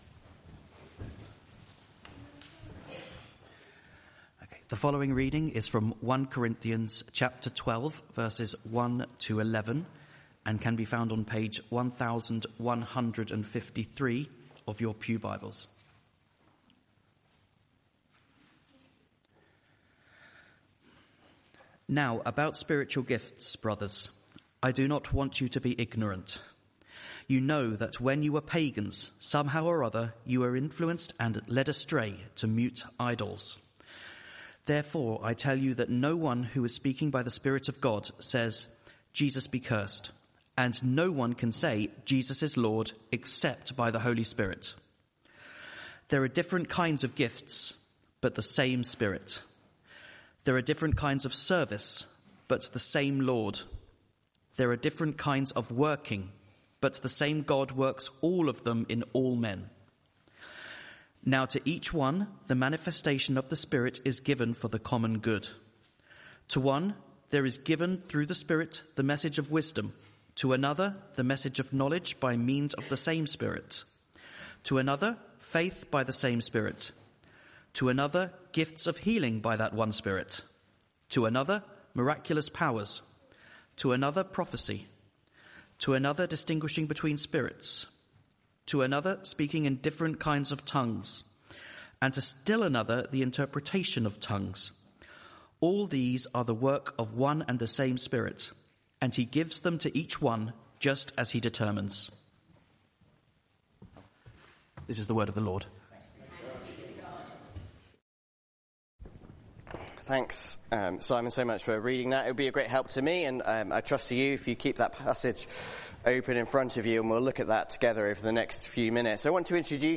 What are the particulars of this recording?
Media for Arborfield Morning Service on Sun 25th May 2025 10:00